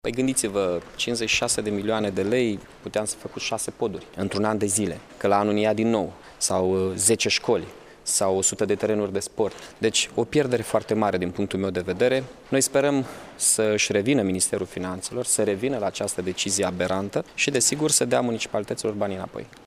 Bugetul Iaşului pe 2018 va fi cu 56 de milioane de lei mai mic decât cel de anul trecut. Ca urmare a reformei fiscale, bugetul se va ridica la 844 de milioane de lei, a precizat, astăzi, într-o conferinţă de presă, primarul Mihai Chirica.